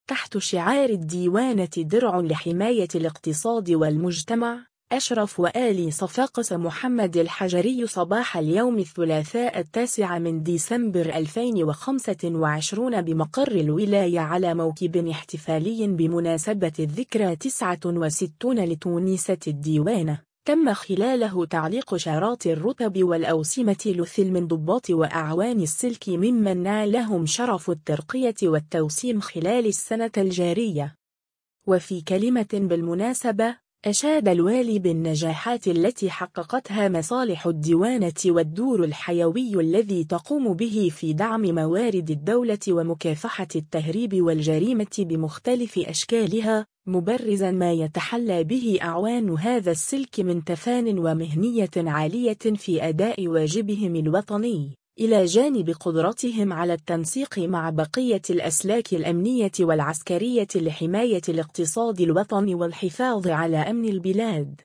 تحت شعار “الديوانة درع لحماية الاقتصاد والمجتمع”، أشرف والي صفاقس محمد الحجري صباح اليوم الثلاثاء 09 ديسمبر 2025 بمقر الولاية على موكب احتفالي بمناسبة الذكرى 69 لتونسة الديوانة، تمّ خلاله تعليق شارات الرتب والأوسمة لثلة من ضباط وأعوان السلك ممن نالهم شرف الترقية والتوسيم خلال السنة الجارية.
و في كلمة بالمناسبة، أشاد الوالي بالنجاحات التي حققتها مصالح الديوانة والدور الحيوي الذي تقوم به في دعم موارد الدولة ومكافحة التهريب والجريمة بمختلف أشكالها، مبرزًا ما يتحلى به أعوان هذا السلك من تفانٍ ومهنية عالية في أداء واجبهم الوطني، إلى جانب قدرتهم على التنسيق مع بقية الأسلاك الأمنية والعسكرية لحماية الاقتصاد الوطني والحفاظ على أمن البلاد.